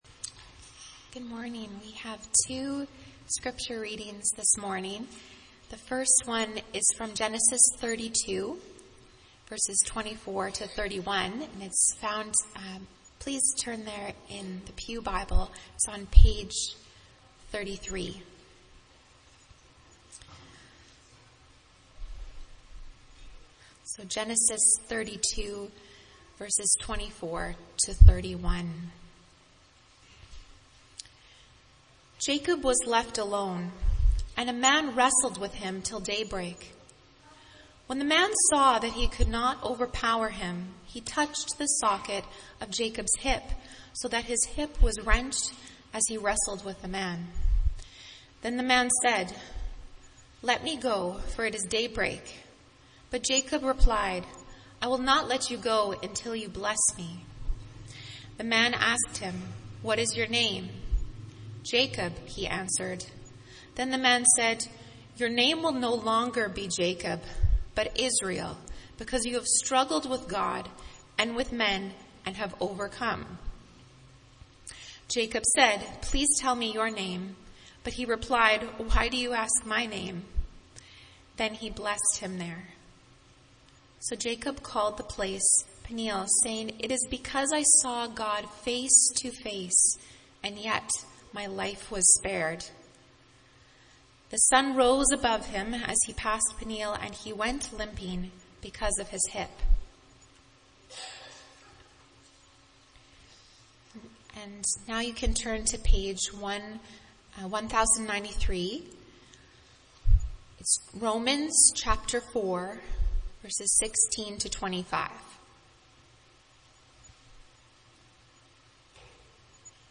MP3 File Size: 18.1 MB Listen to Sermon: Download/Play Sermon MP3